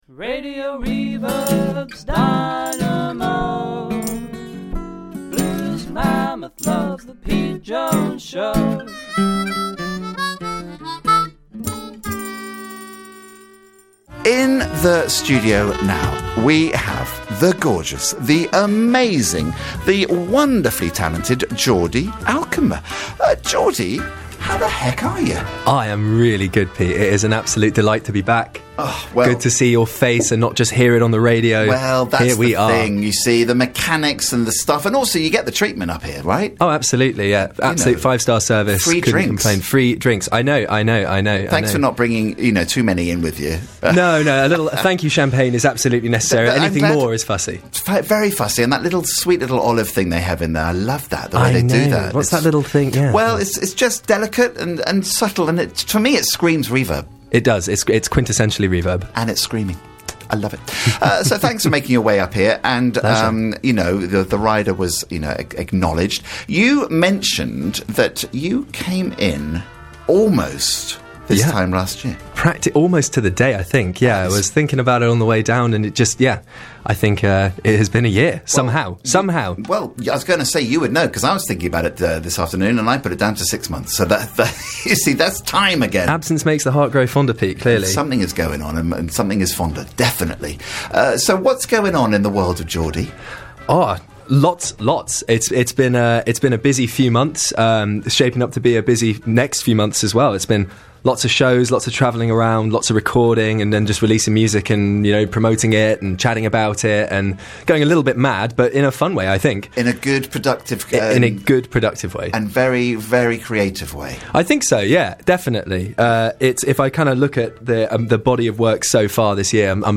(studio track)